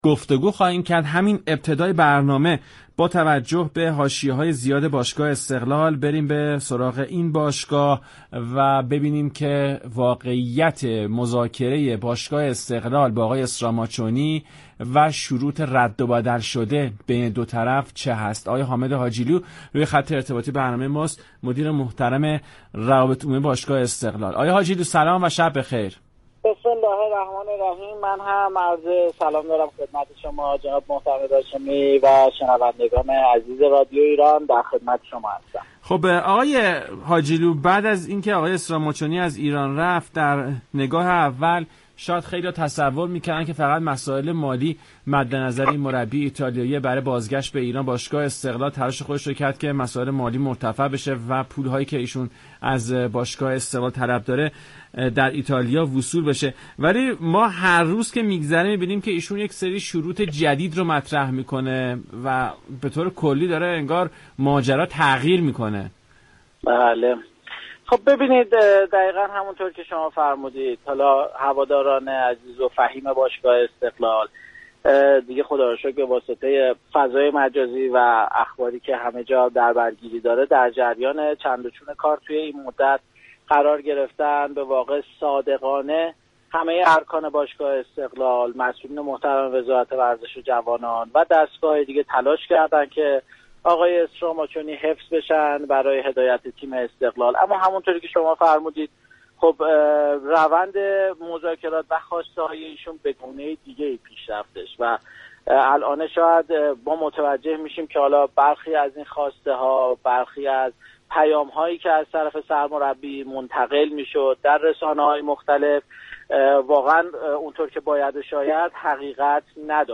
برنامه «ورزش ایران» شنبه تا پنج شنبه هر هفته ساعت 19:05 از رادیو ایران پخش می شود.